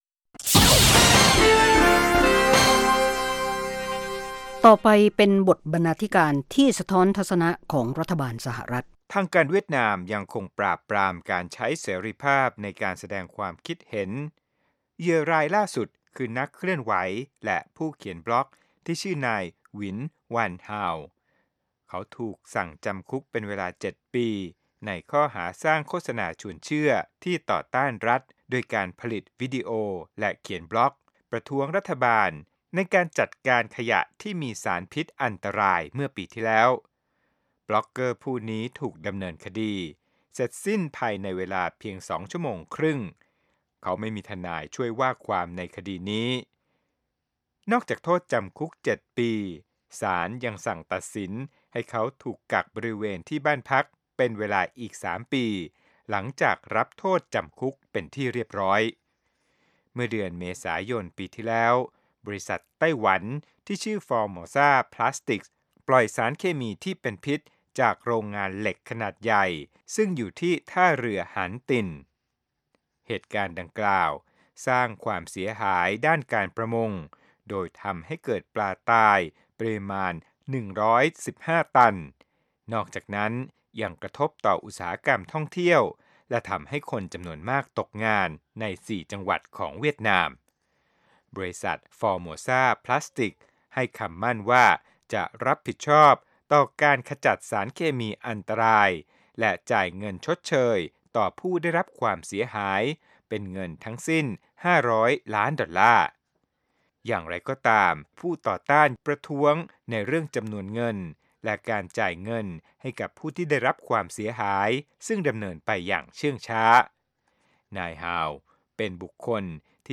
วาไรตี้โชว์ ฟังสบายๆ สำหรับวันหยุดสุดสัปดาห์ เริ่มด้วยการประมวลข่าวในรอบสัปดาห์ รายงานเกี่ยวกับชุมชนไทยในอเมริกา หรือเรื่องน่ารู้ต่างๆ ส่งท้ายด้วยรายการบันเทิง วิจารณ์ภาพยนตร์และเพลง